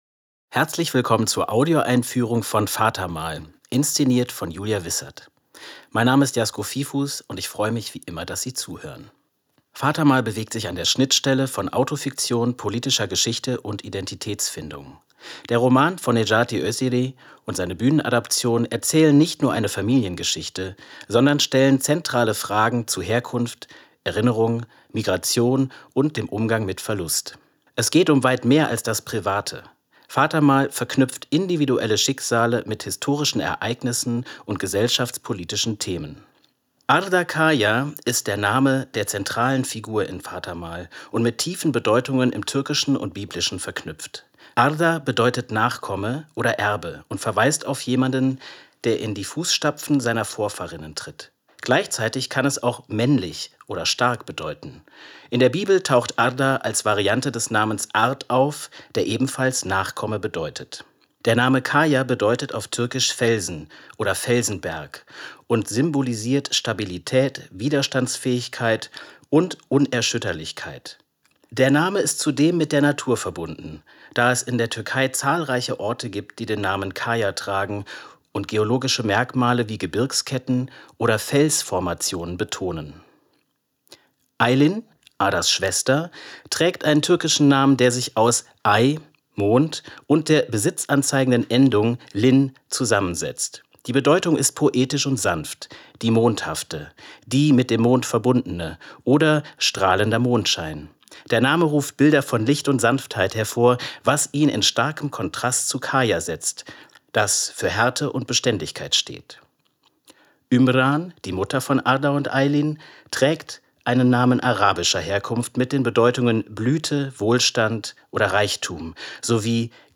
tdo_einfuehrung_vatermal.mp3